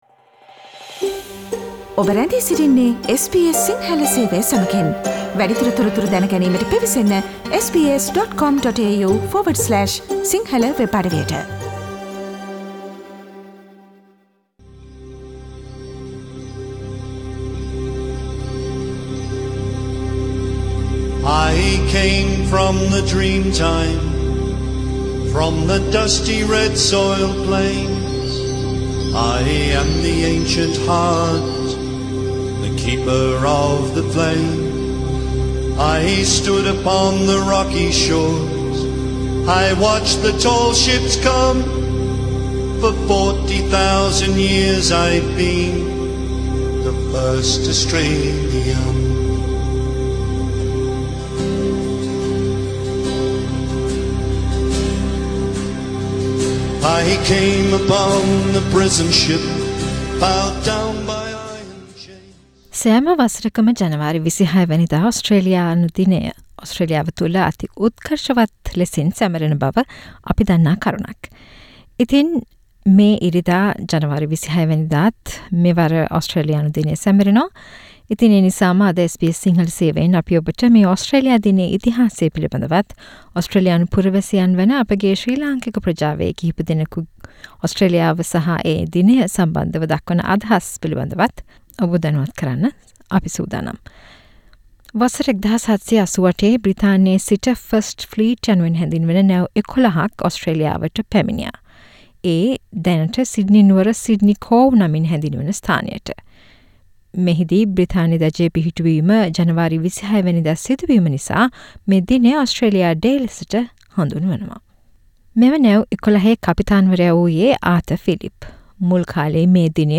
ජනවාරි 26 වැනිදාට යෙදෙන ඕස්ට්‍රේලියානු දිනය වෙනුවෙන් එහි ඉතිහාසය හා ශ්‍රී ලාංකික ප්‍රජාවේ අදහස් සමග SBS සිංහල ගුවන් විදුලිය ගෙන එන විශේෂාංගයක්